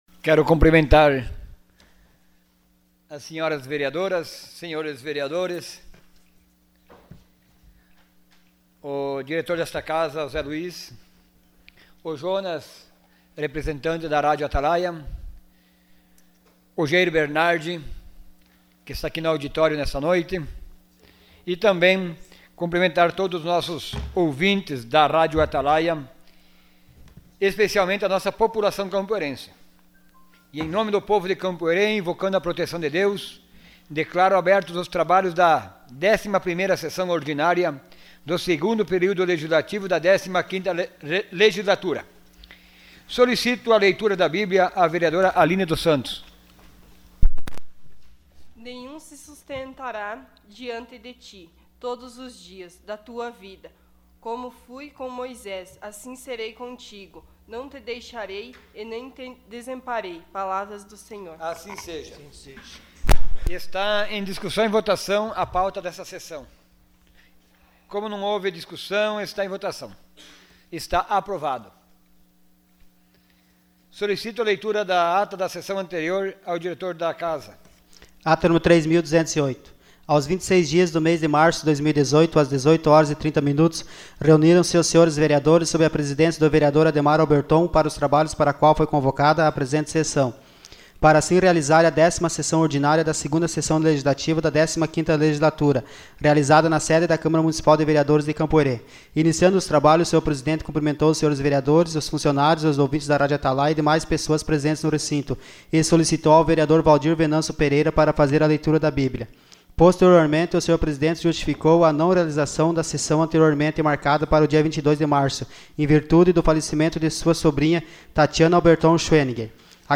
Sessão Ordinária dia 27 de março de 2018.